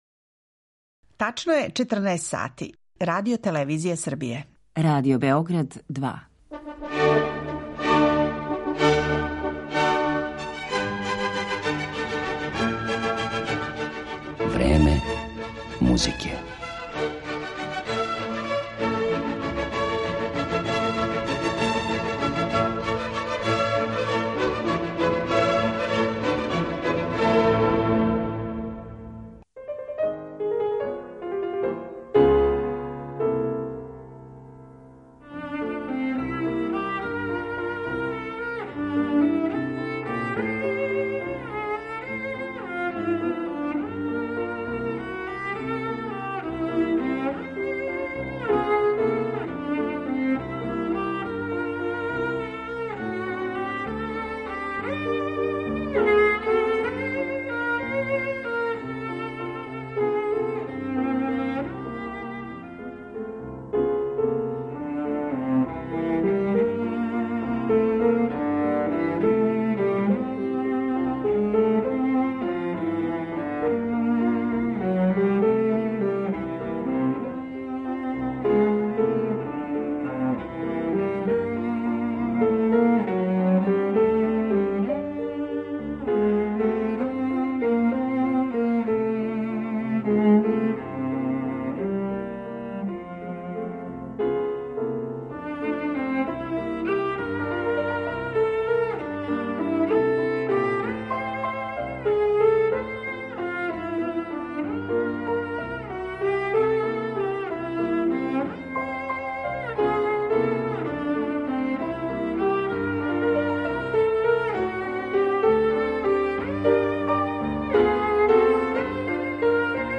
припремила је избор музике из филмова у којима је овај великан седме уметности не само играо, режирао их и продуцирао, већ је за њих писао и музику.